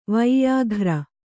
♪ vaiyāghra